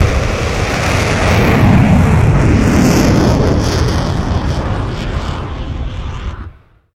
TheExperienceLight - A lightened version of the official VIP The Experience soundpack.
Launch.wav